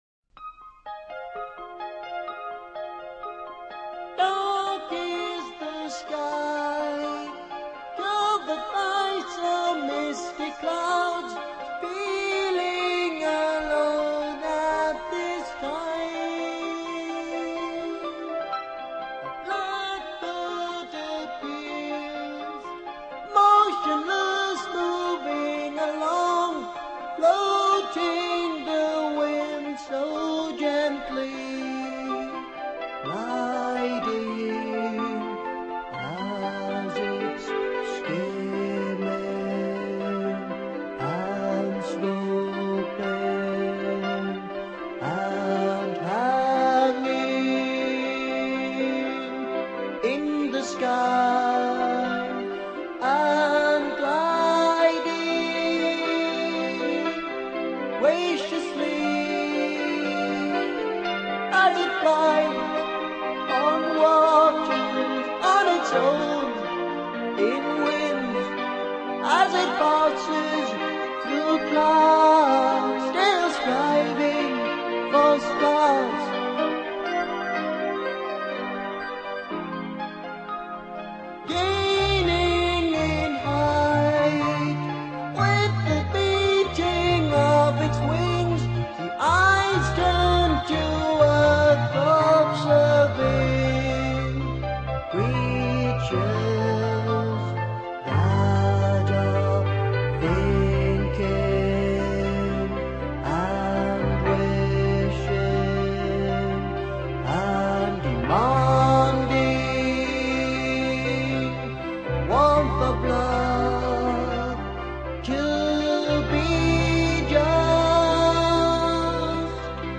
They played so called classic, progressive rock.
piano, keyboards
drums, percussion
guitars
bass, bass pedals, b-vox
voice, flute, saxophones, oboe